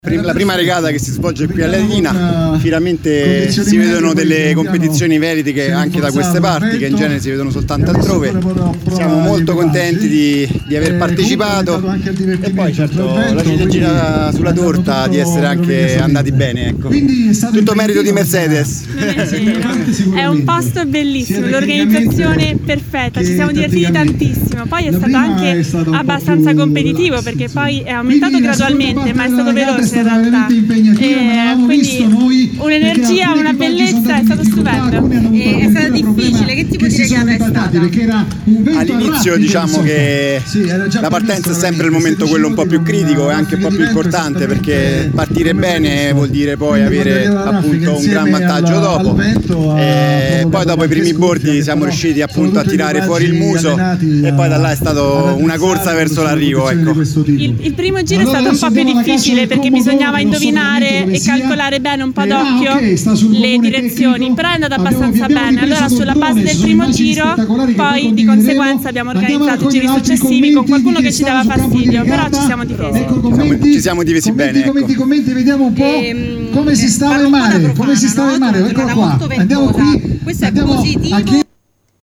Le prime impressioni dei due vincitori